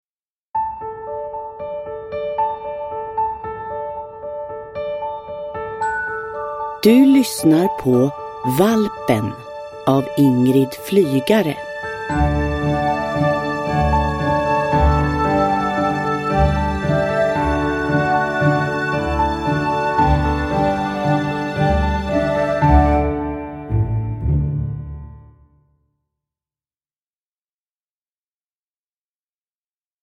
Valpen – Ljudbok